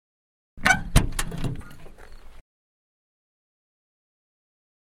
Звук открывающейся двери фургона